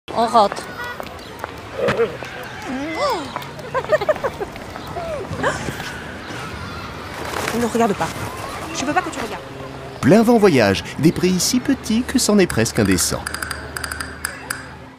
Ma prestation voix-off drôle et naturelle pour Plein Vent Voyage
Drôle, sympa et naturel.
Spot télé de l’agence Plein Vent Voyage produit par Wooww Your Life.
Avec une hauteur de voix médium, j’ai su donner une tonalité drôle, sympa et naturelle, tout à fait en phase avec l’esprit vacances et détente de Plein Vent.
C’est pourquoi, pour Plein Vent Voyage, j’ai adopté un ton enjoué et convivial, pour transmettre l’enthousiasme des vacances et le plaisir du dépaysement.